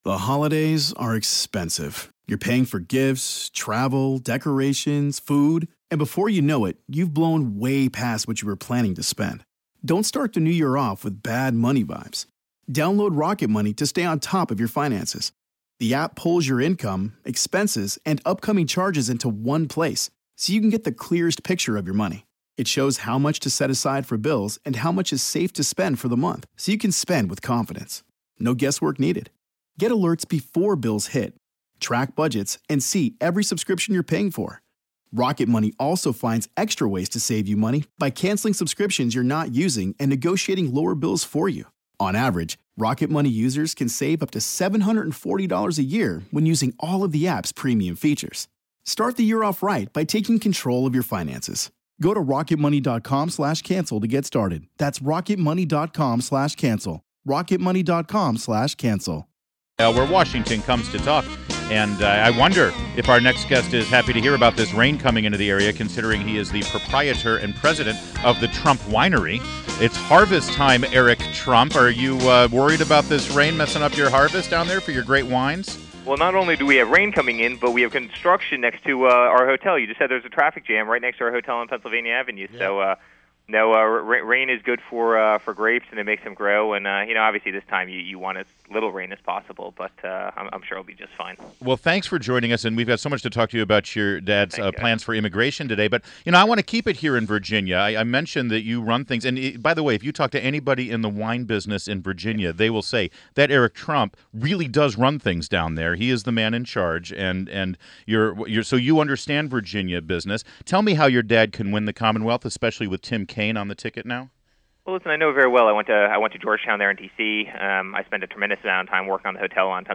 WMAL Interview - ERIC TRUMP - 08.31.16